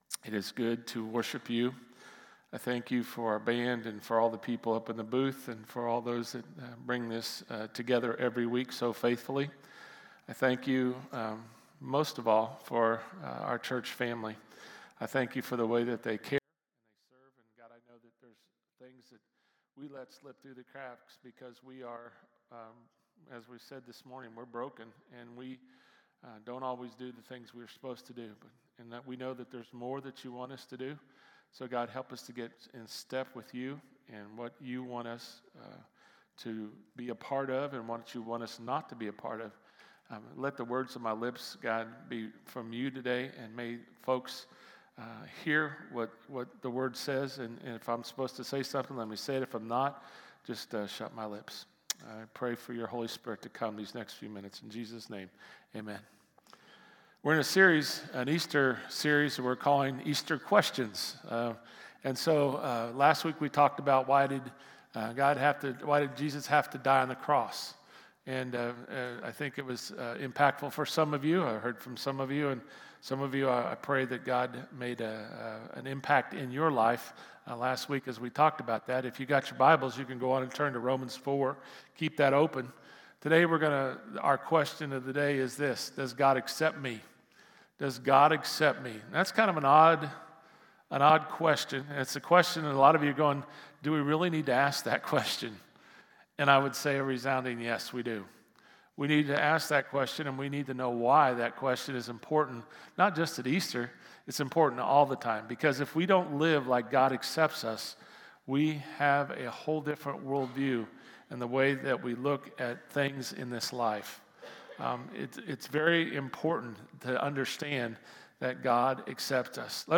sermon-4-6-24.mp3